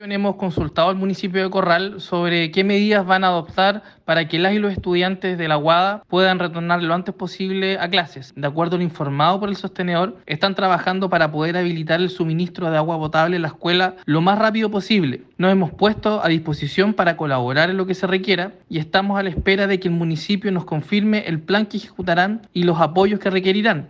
En este sentido, el seremi de Educación, Juan Pablo Gerter, aseguró que están en contacto con el municipio para los apoyos que requieran.